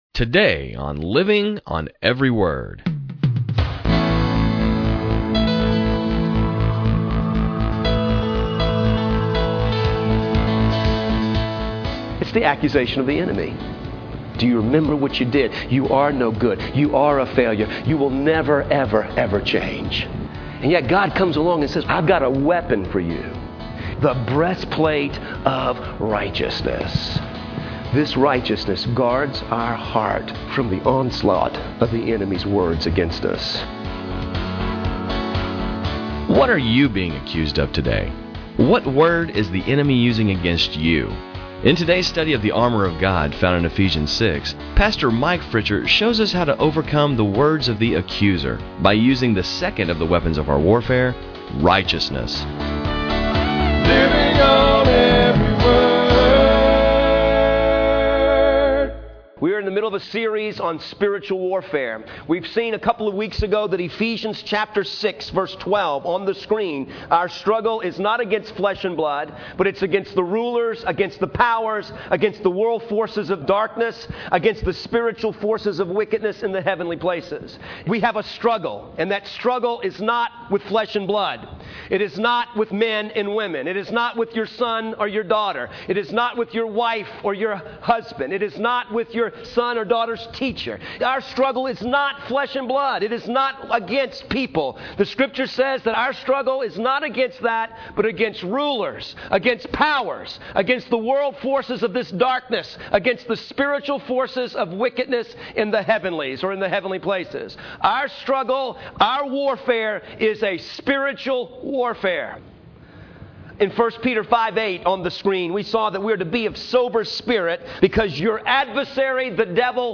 In today's study, we discuss the breastplate of righteousness. Putting on this weapon protects our hearts from the Enemy's words against us!